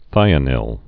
(thīə-nĭl)